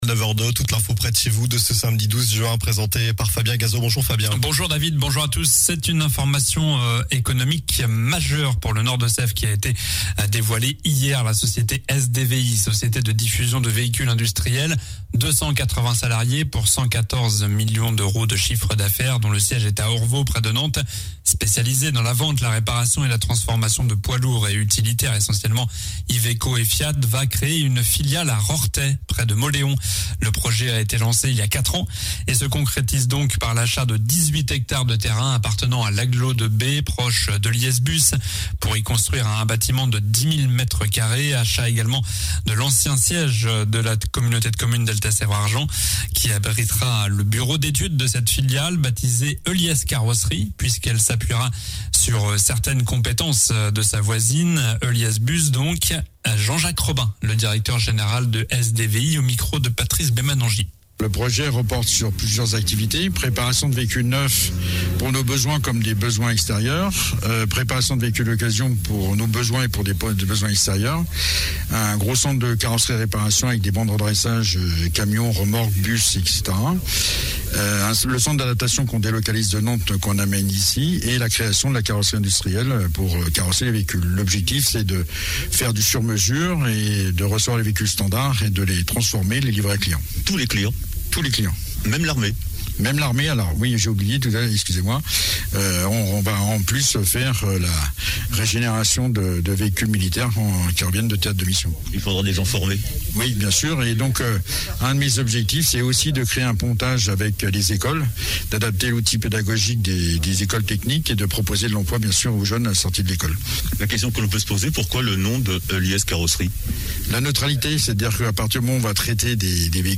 Journal du samedi 12 juin